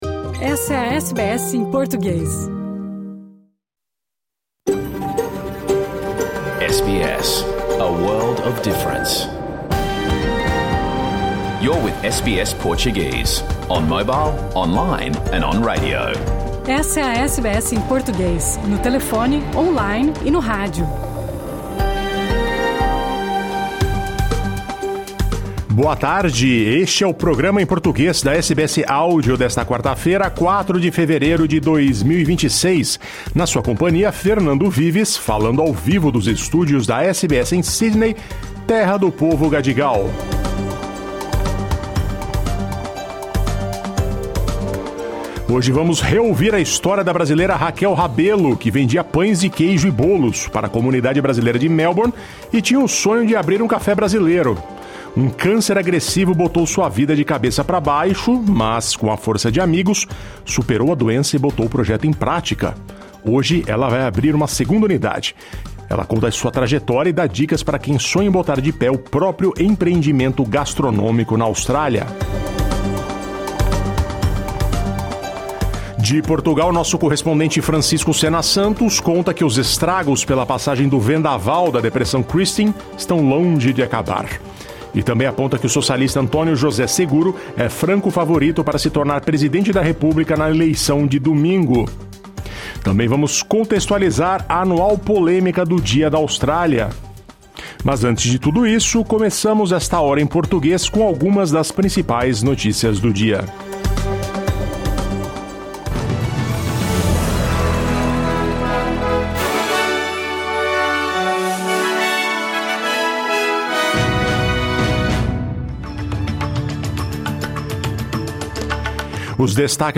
O programa em português que foi ao ar ao vivo pela SBS 2 em toda a Austrália. As notícias do dia.